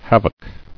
[hav·oc]